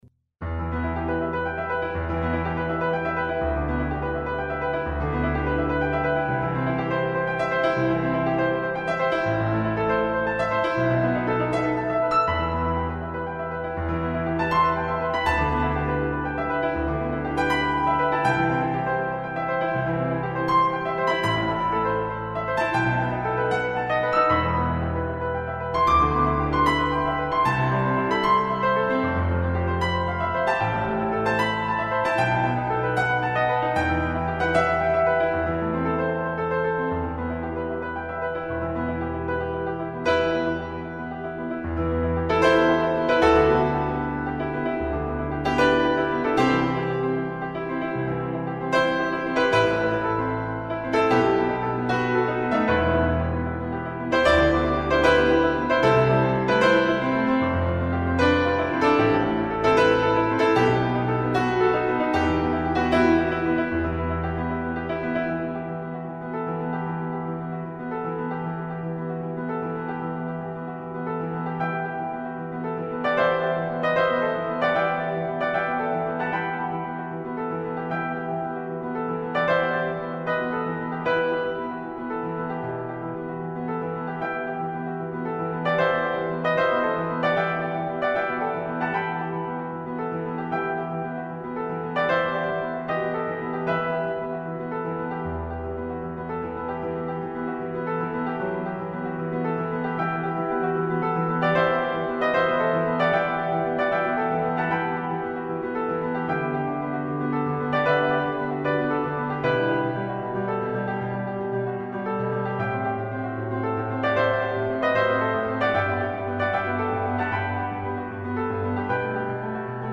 picked up a faster pace than before.
piano2.mp3